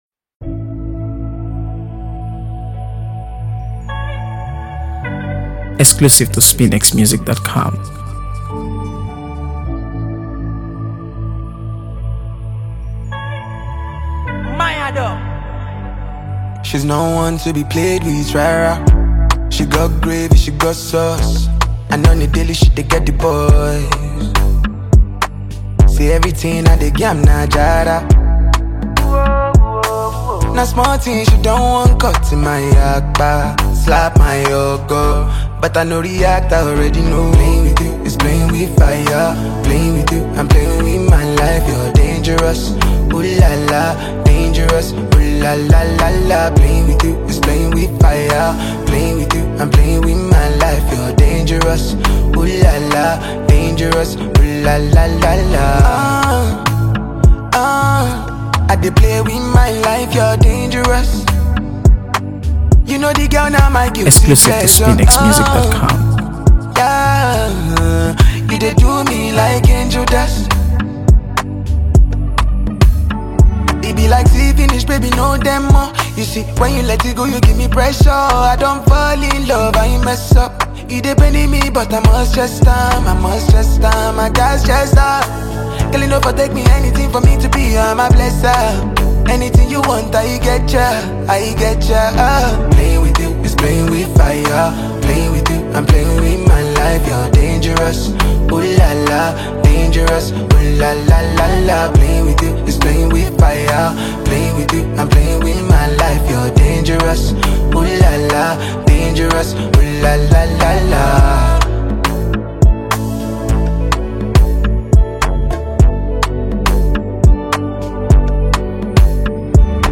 AfroBeats | AfroBeats songs
Nigerian singer and songwriter
delivers the perfect mix of energy, style, and emotion